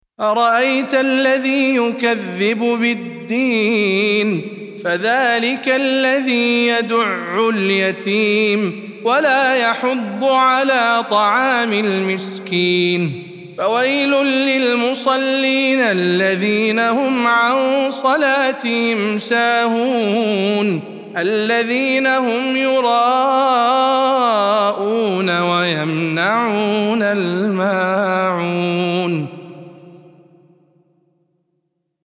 سورة الماعون - رواية إدريس عن خلف العاشر